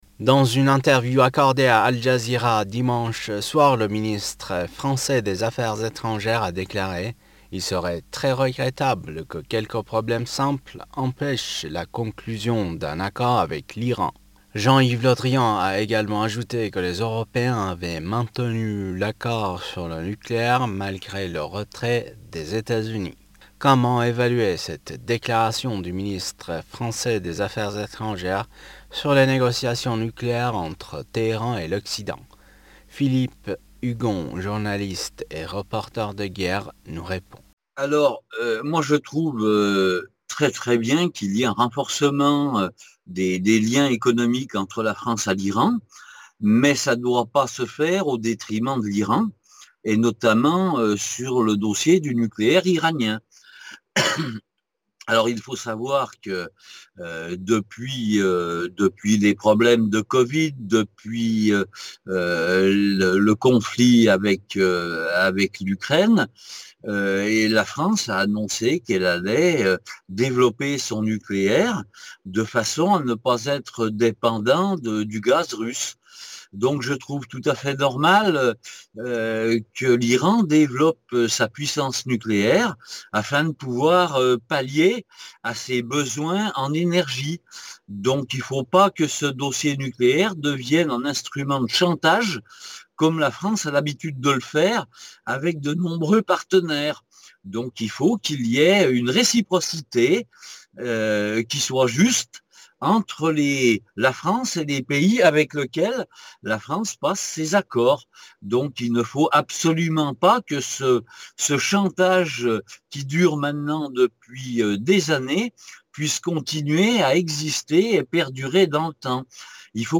reporter de guerre.